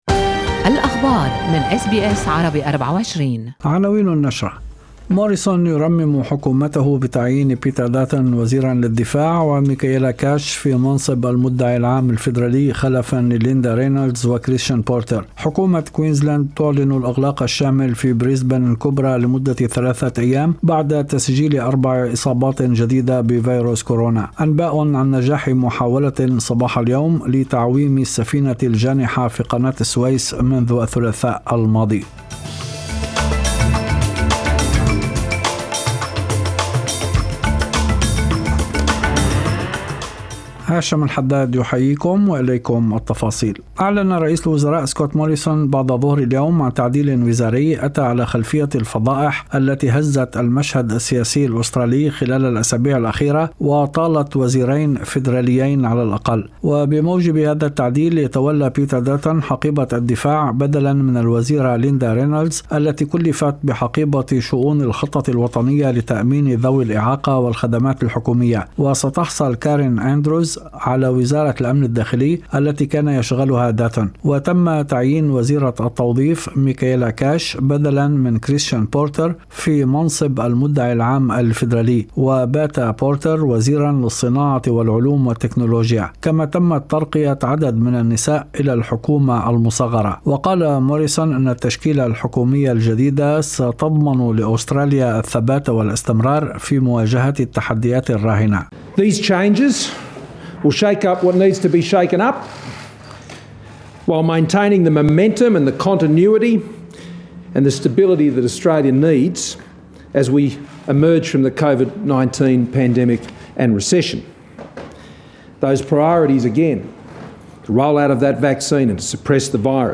نشرة أخبار المساء 29/3/2021